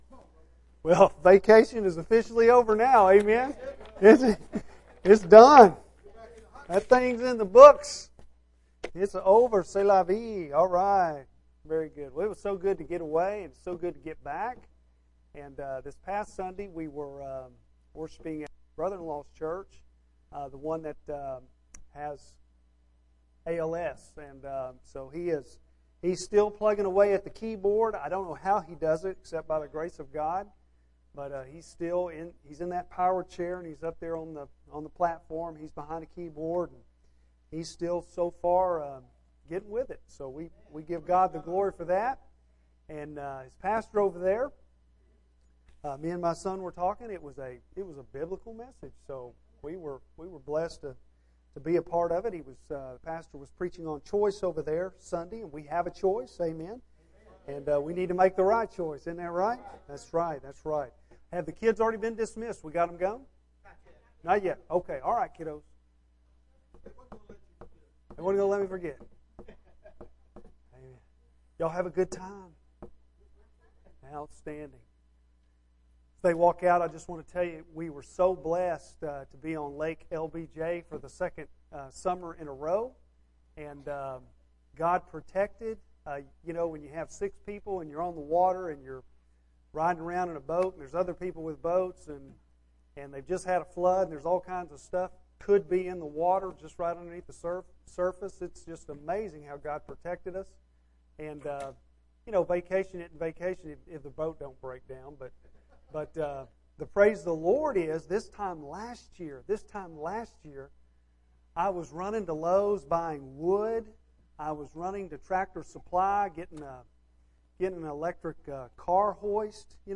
Bible Text: Matthew 14:14-21 | Preacher